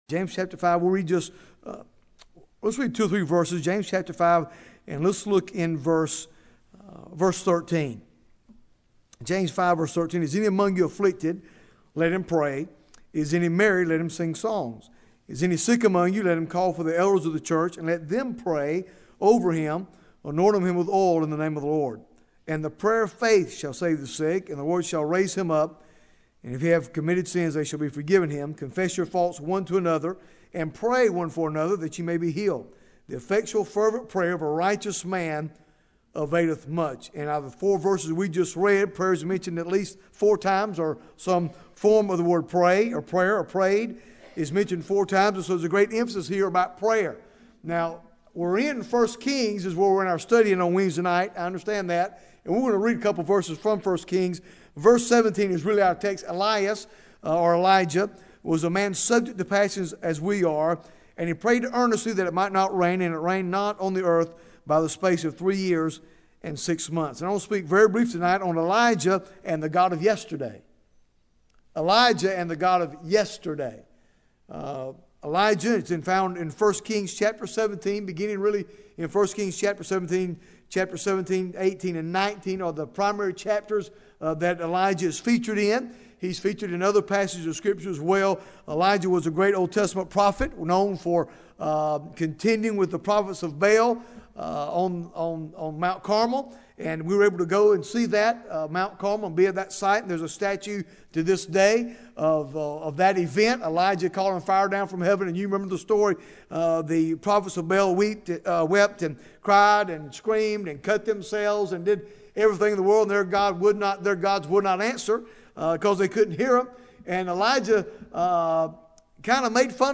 Bible Text: James 5 | Preacher